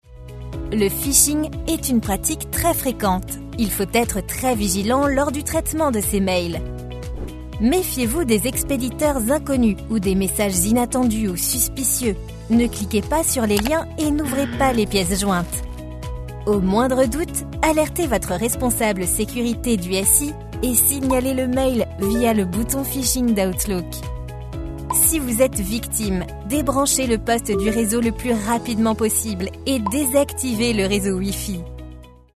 Female
French (European), French (Parisienne)
My voice is young and medium.
Corporate
Naturelle, Explicative